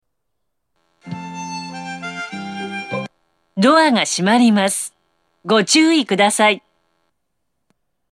発車メロディー